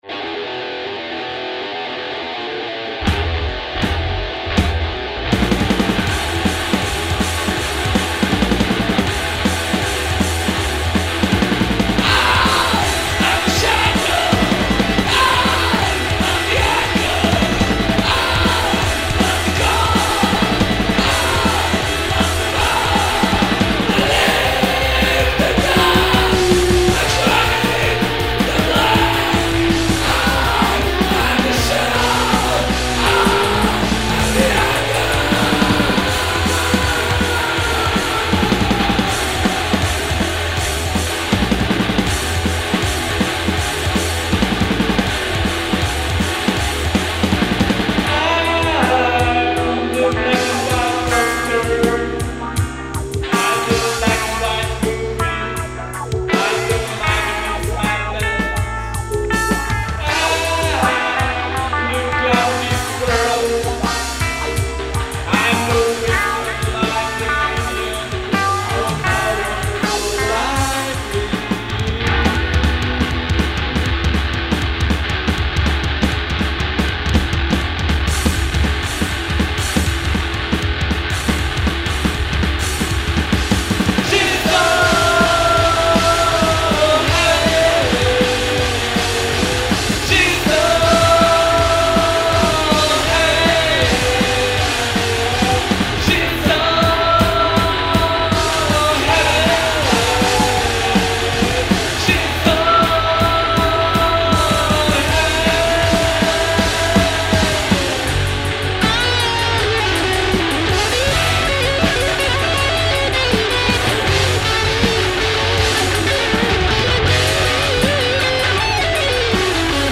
gitara, vokal
bass, vokal
bubnjevi